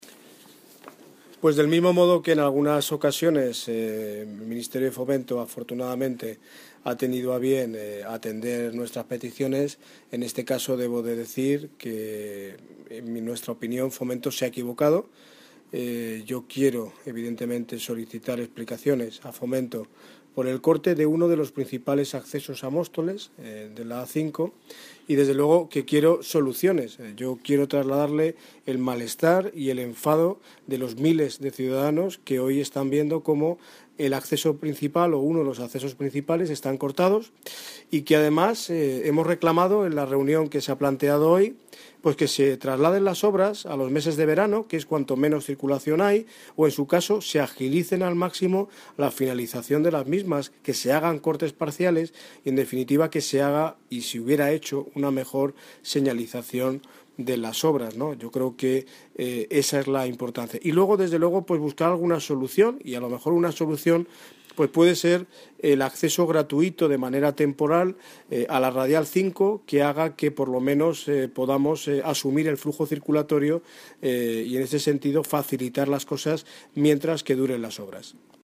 Audio - Daniel Ortiz (Alcalde de Móstoles) Sobre Solución Atascos Nacional 5